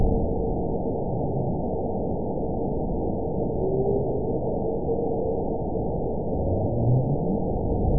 event 921206 date 05/02/24 time 04:53:05 GMT (1 year, 1 month ago) score 9.55 location TSS-AB09 detected by nrw target species NRW annotations +NRW Spectrogram: Frequency (kHz) vs. Time (s) audio not available .wav